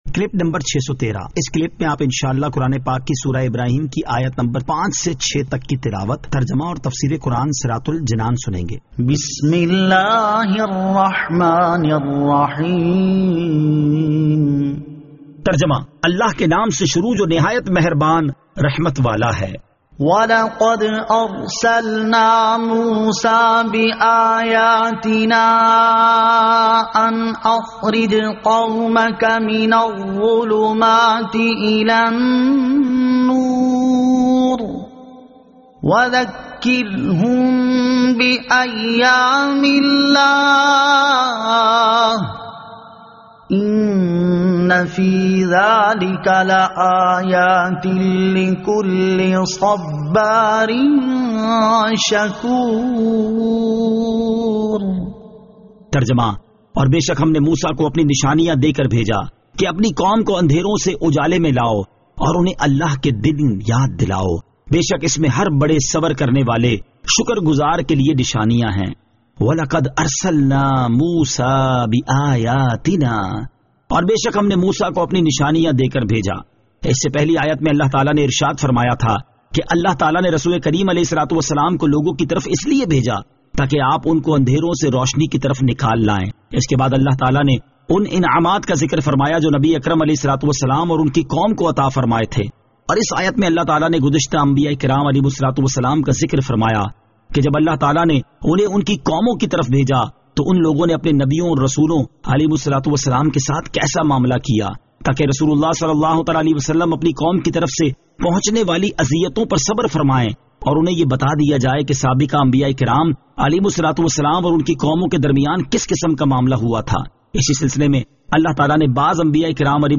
Surah Ibrahim Ayat 05 To 06 Tilawat , Tarjama , Tafseer
2021 MP3 MP4 MP4 Share سُوَّرۃُ ابٗرَاھِیم آیت 05 تا 06 تلاوت ، ترجمہ ، تفسیر ۔